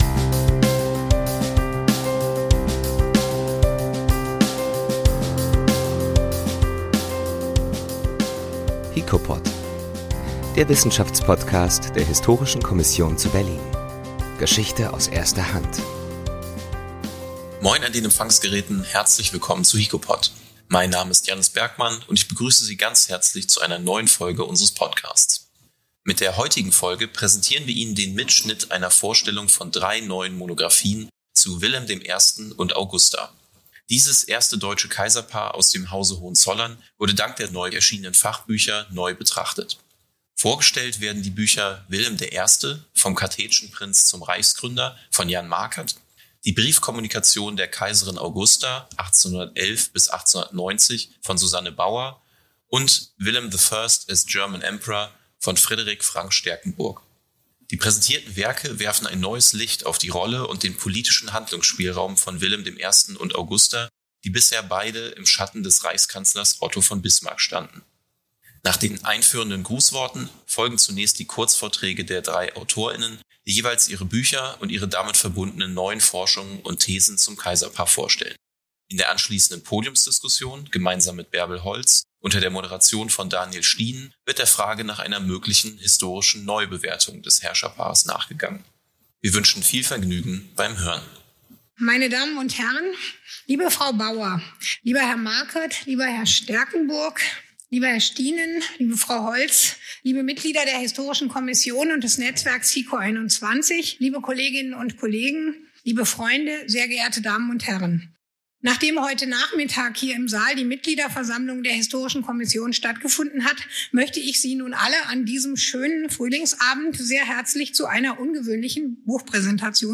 in ihren Kurzvorträgen nach.